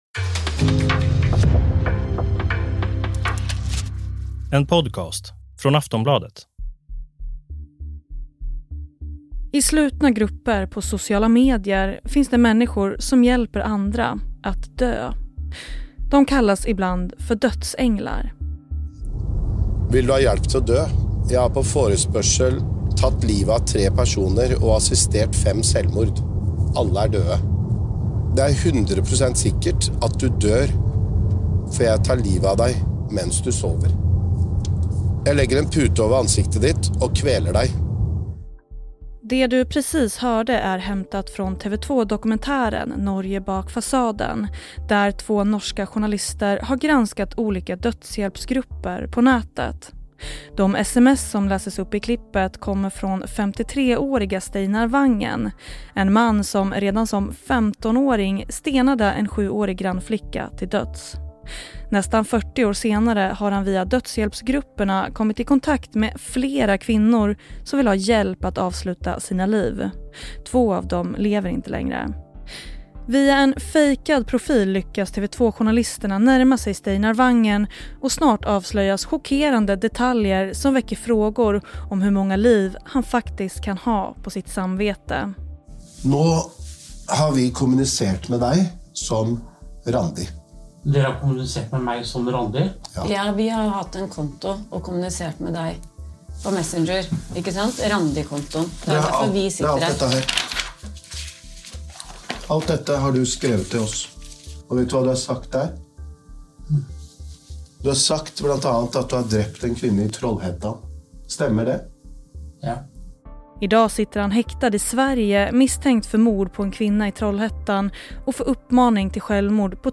Klipp i avsnittet: TV 2 dokumentären Norge bak fasaden.